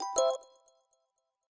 session-ios / Signal / AudioFiles / messageReceivedSounds / hello-quiet.aifc
hello-quiet.aifc